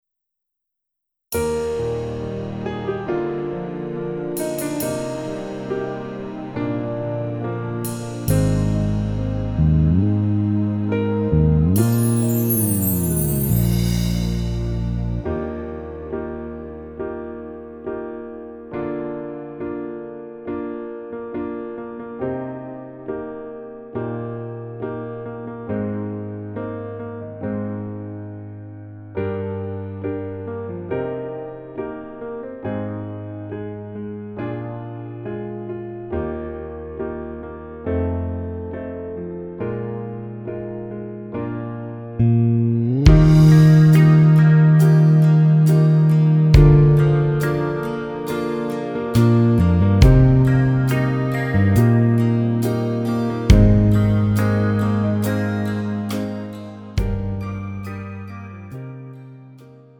음정 (-1키)
장르 가요 구분 Pro MR